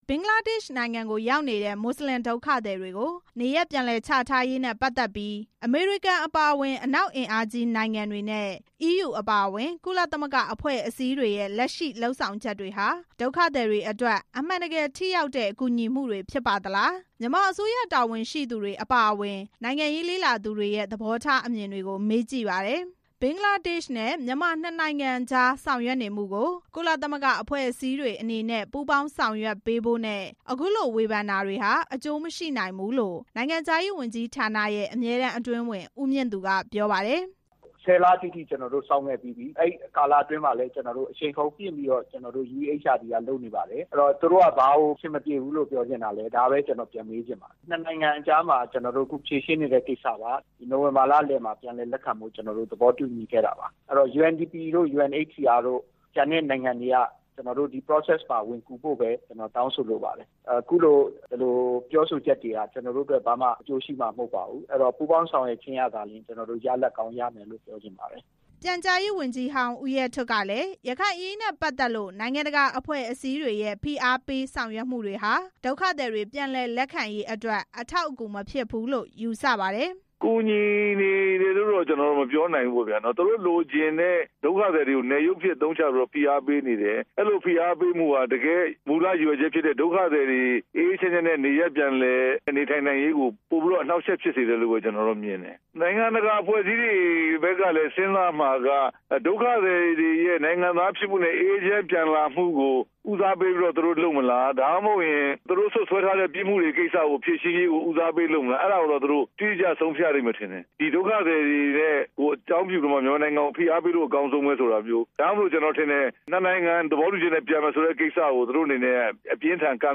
မြန်မာအစိုးရ တာဝန်ရှိသူတွေအပါအဝင် နိုင်ငံရေးလေ့လာသူတွေရဲ့ သဘောထားအမြင်တွေကို မေးကြည့်ပါတယ်။